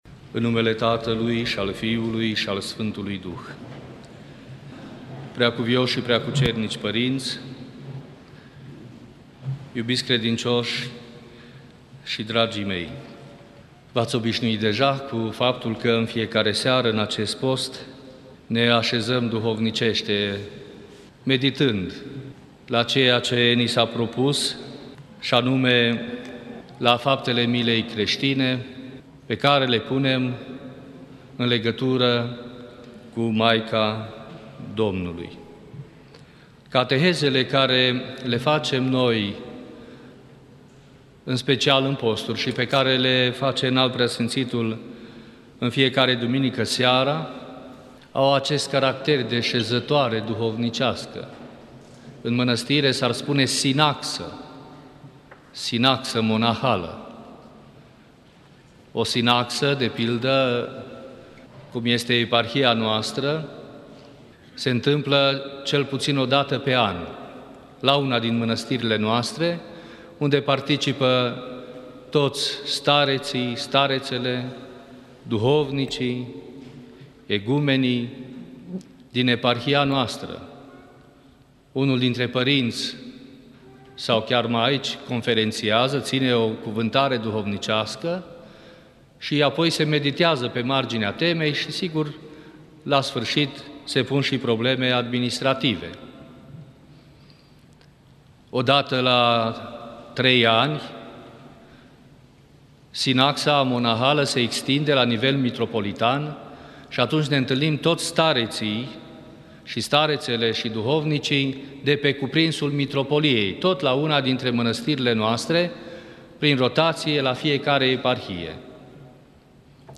Maica Domnului Cel răstignit – cateheză
Cuvântul de învățătură al PCuv.